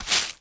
sweeping_broom_leaves_01.wav